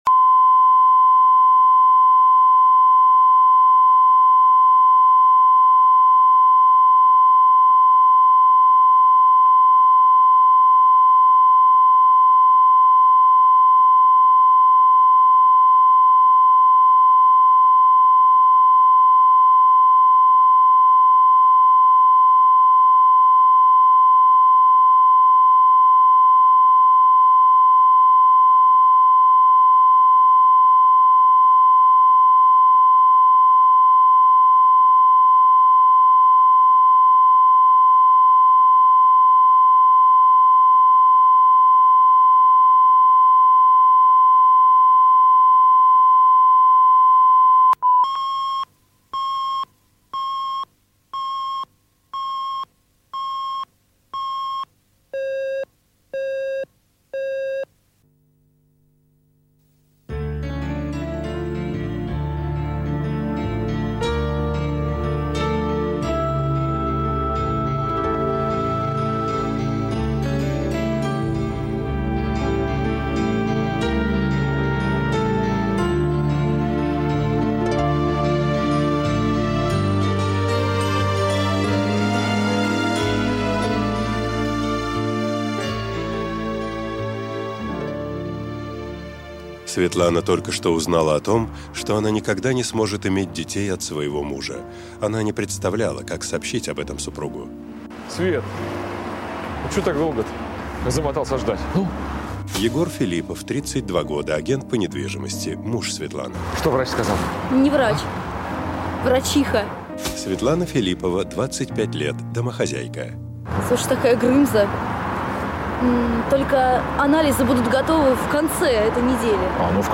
Аудиокнига Чудо природы | Библиотека аудиокниг